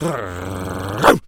Animal_Impersonations
wolf_bark_02.wav